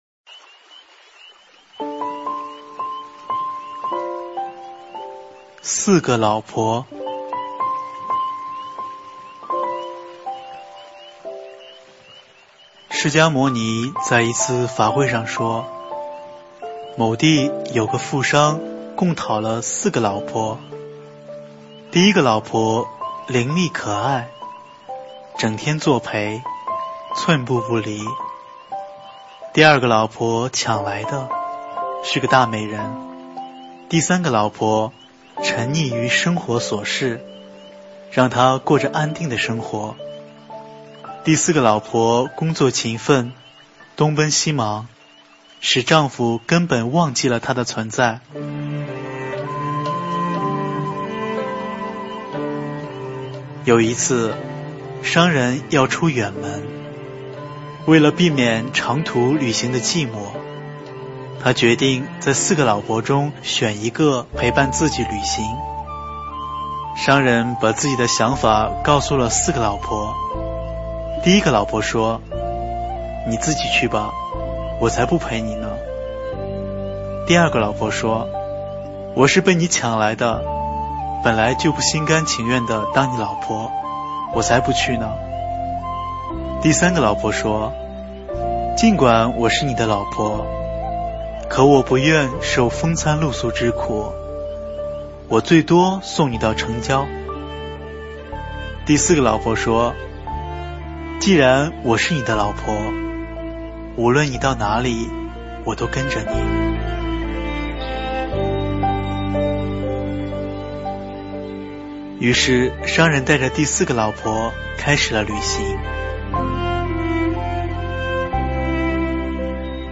四个老婆--有声佛书